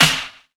OZ-Clap 4.wav